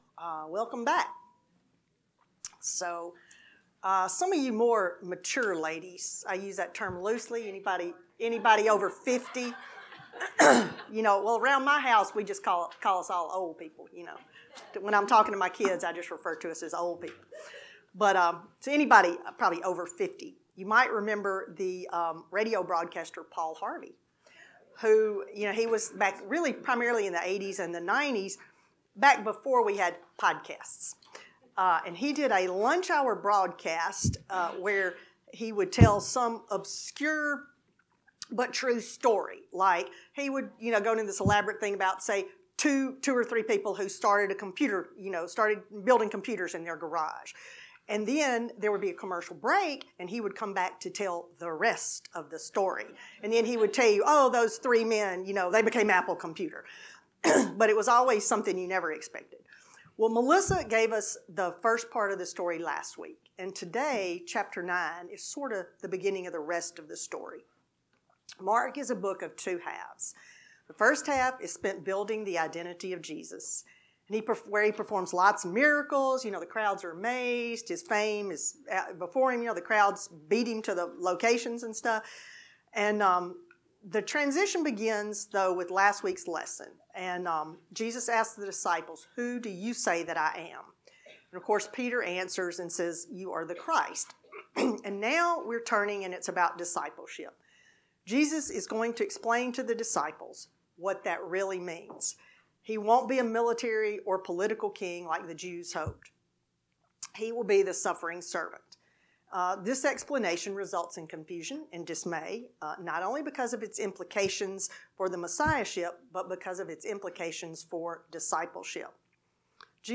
Lesson 13